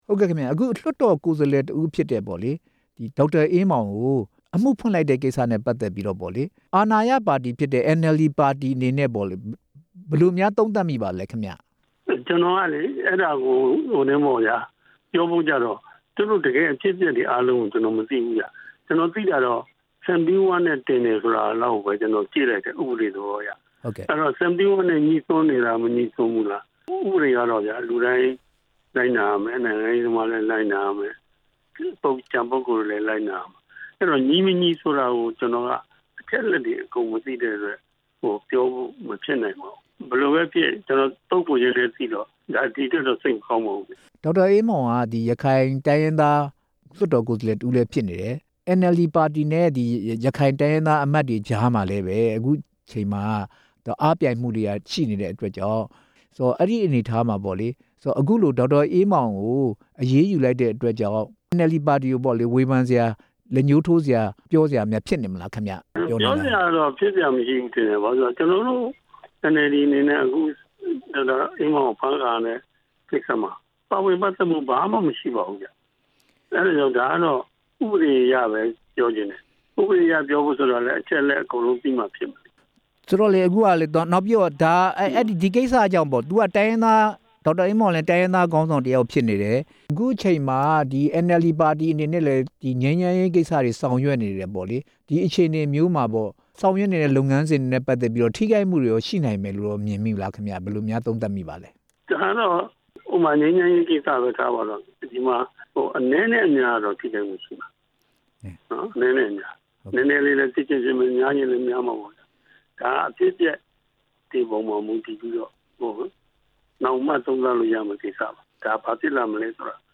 ရခိုင်ခေါင်းဆောင် ဒေါက်တာအေးမောင်ကို ဖမ်းဆီးမှု အာဏာရအန်အယ်လ်ဒီပါတီနဲ့ မေးမြန်းချက်